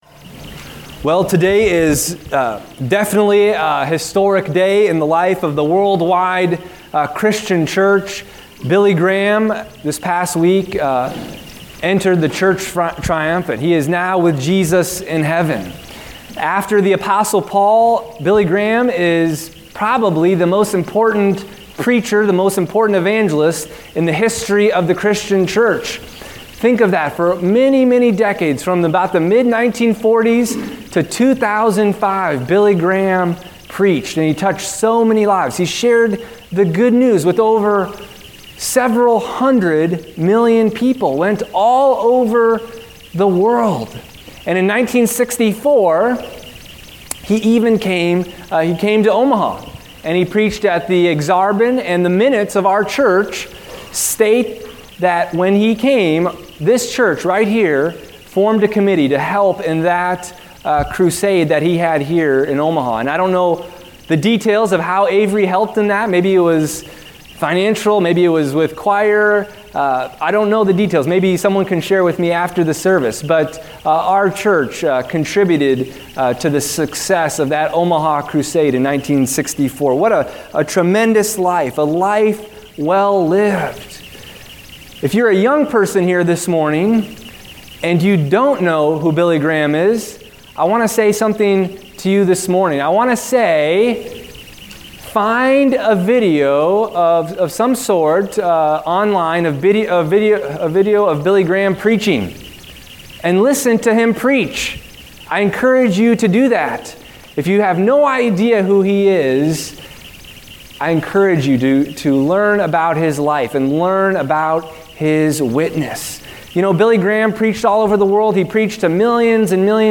Service Type: Lent